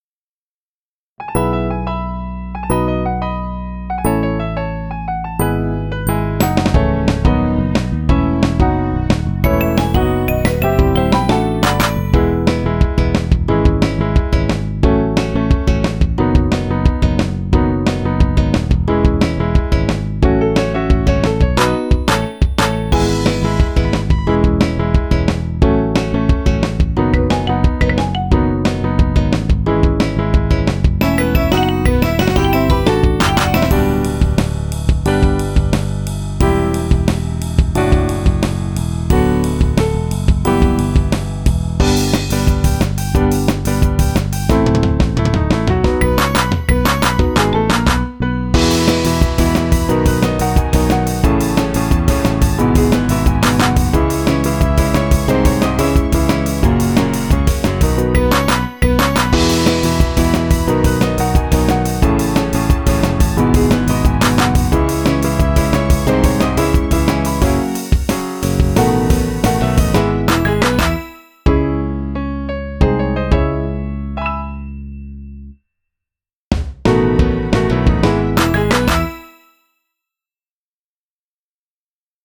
（BPM：157）
inst音源 inst音源、カラオケ音源です 個人の範疇をこえて利用するときは、以下を守ってご利用ください。